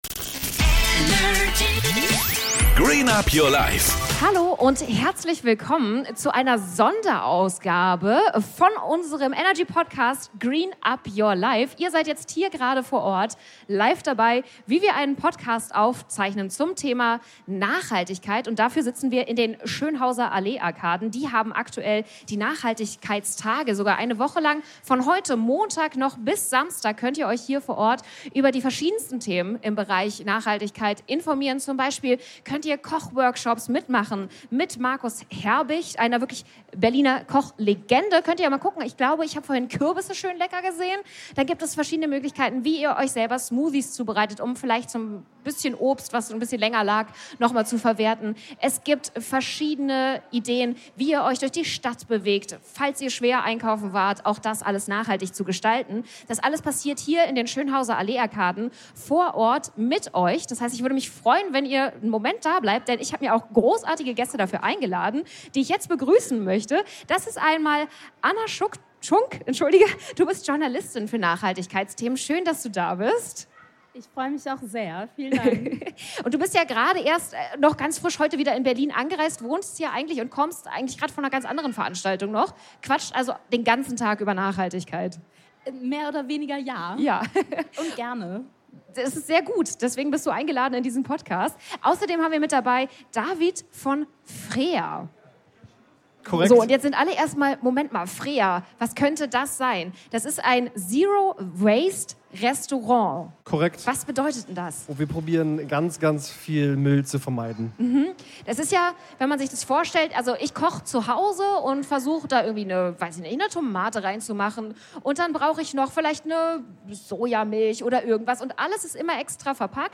#31 Sonderfolge: Live-Podcast ~ Green Up Your Life Podcast